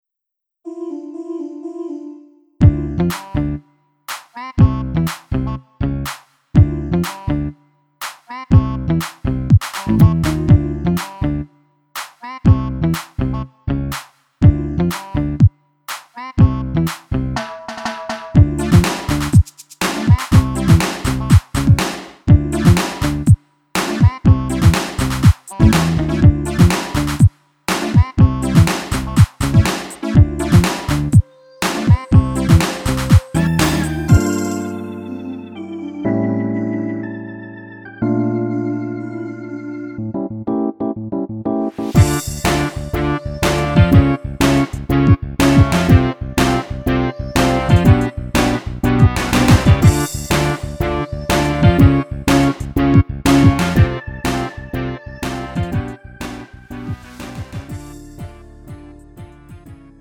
음정 원키 장르 가요